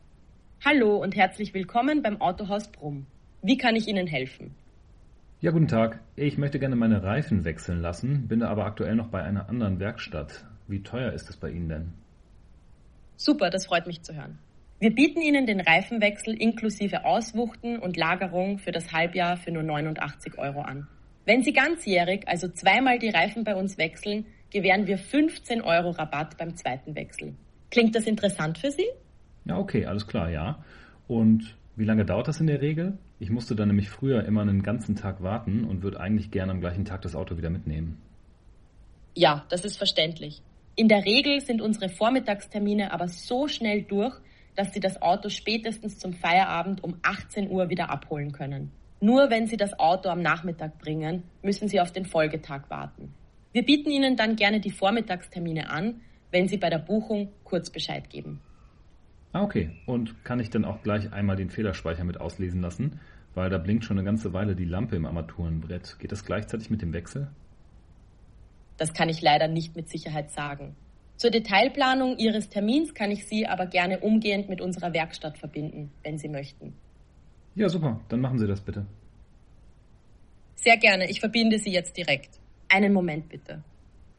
Aber wie klingt der KI-Bot am Telefon? hier ein paar Praxisbeispiele
demo-call-smarter-empfang.mp3